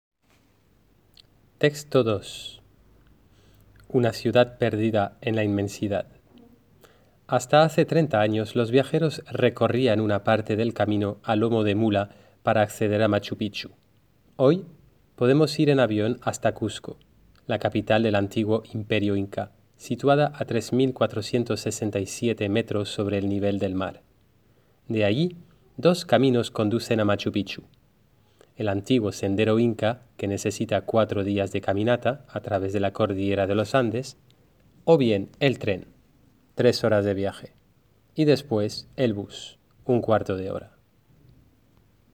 Lectura del texto por el profesor: